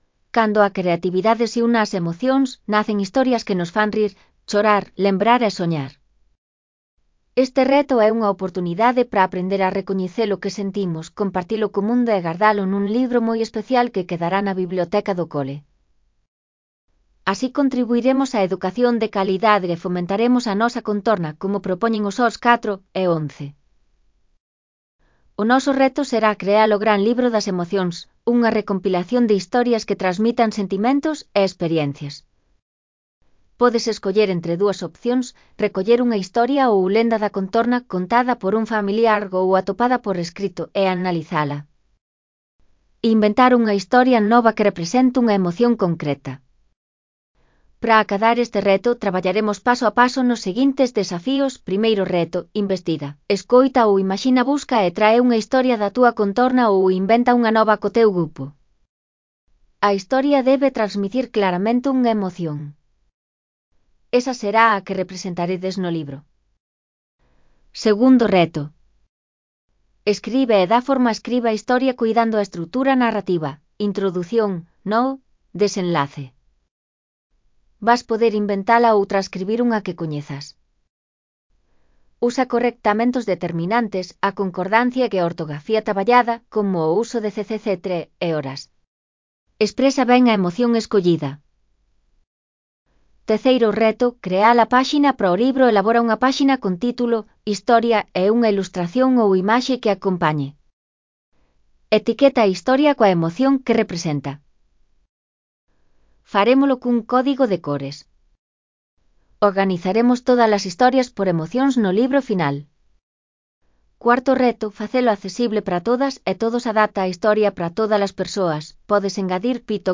Elaboración propia (proxecto cREAgal) con apoio de IA voz sintética xerada co modelo Celtia. O reto (CC BY-NC-SA)